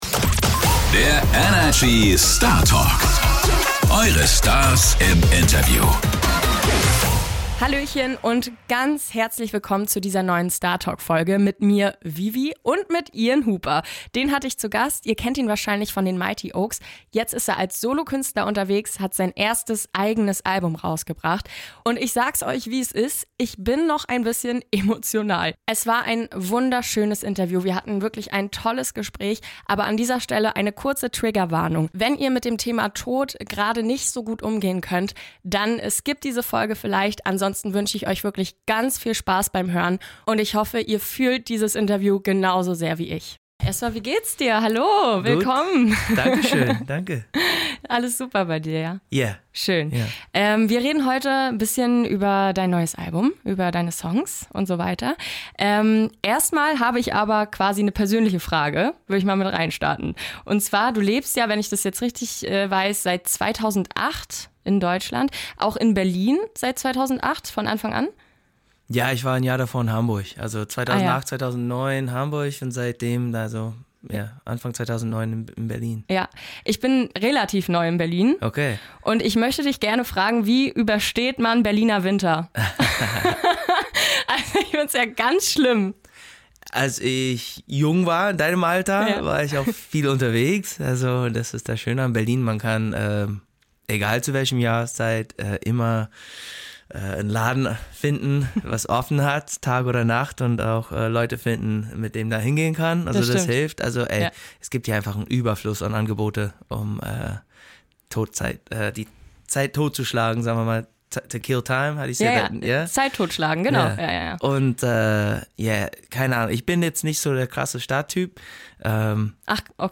Beschreibung vor 2 Jahren Vor 10 Jahren hatte er seinen großen Durchbruch mit seiner Band "Mighty Oaks", jetzt ist er seit einiger Zeit auch als Solo-Künstler erfolgreich. Wir sprechen in dieser Folge mit Ian Hooper über sein neues Album, das unfassbar ehrlich und persönlich ist, über das Allein sein und über Verlust.